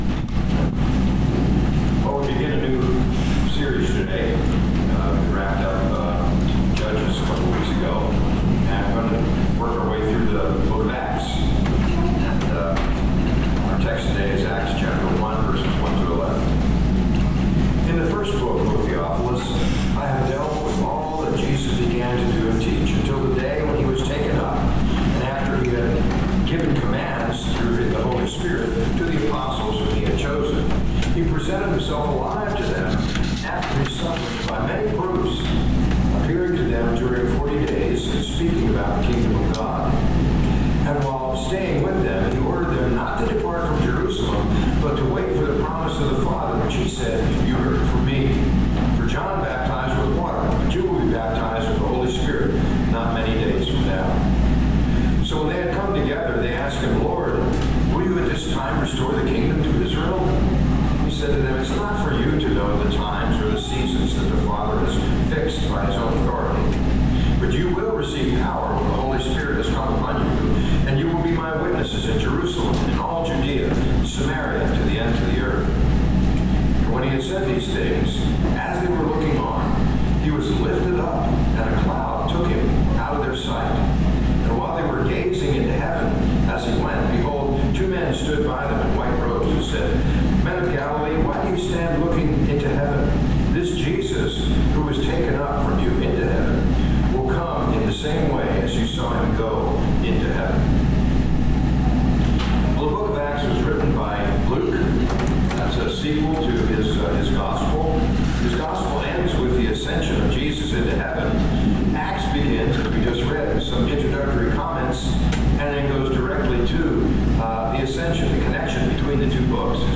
Sunday Worship 11 AM & 6 PM